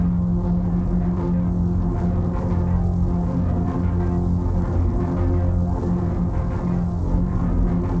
Index of /musicradar/dystopian-drone-samples/Tempo Loops/90bpm
DD_TempoDroneC_90-F.wav